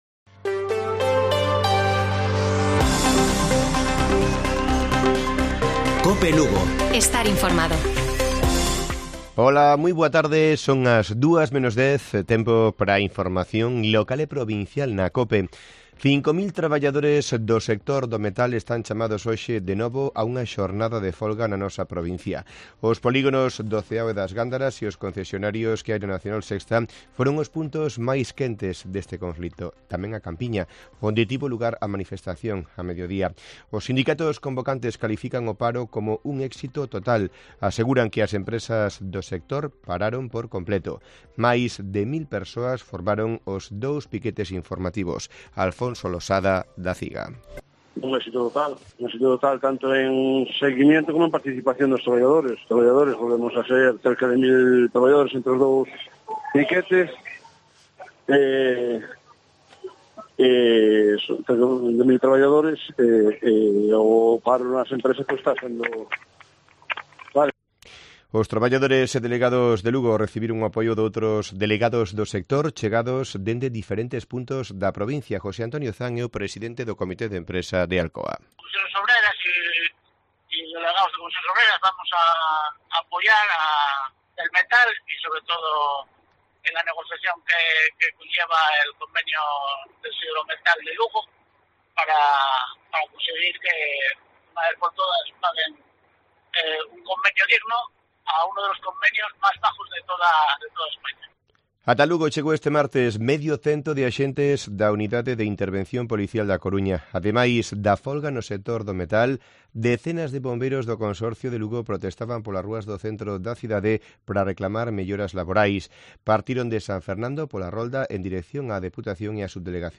Informativo Mediodía de Cope Lugo. 23 DE MAYO. 13:50 horas